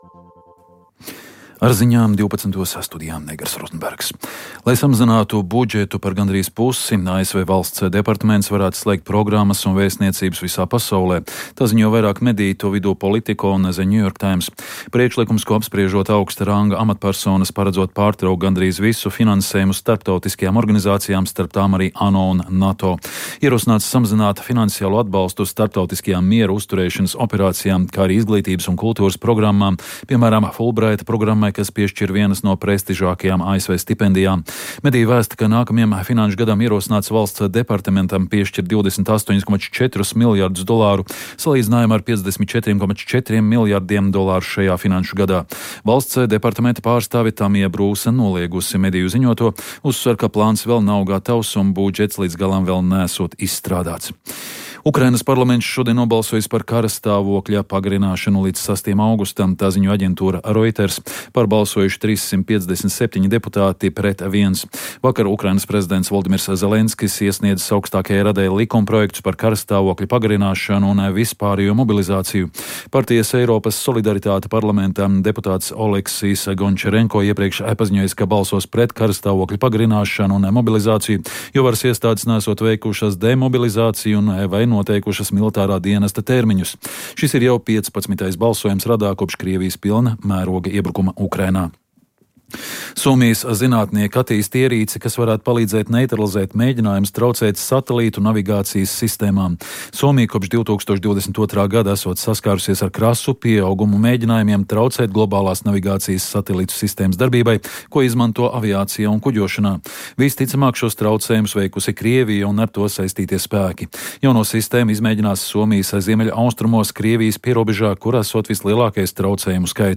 Informatīvi analītiska programma par aktuālo un svarīgo Latvijā un pasaulē. Ziņu dienesta korespondenti ir klāt vietās, kur pieņem lēmumus un risinās notikumi, lai par to visu stāstītu tiešraidēs, reportāžās un izsvērtos komentāros.